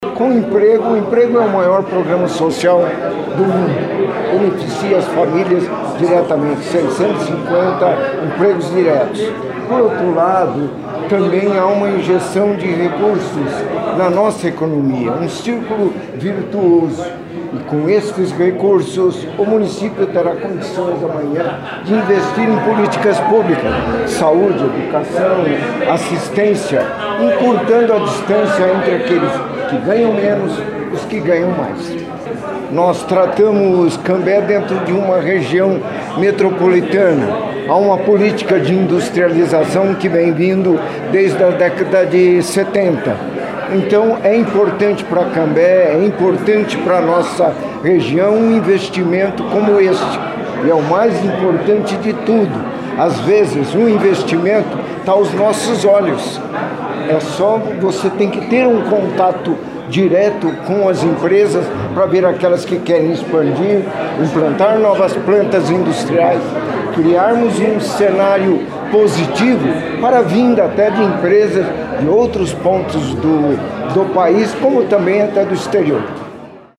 O Prefeito José do Carmo Garcia destacou a geração de empregos.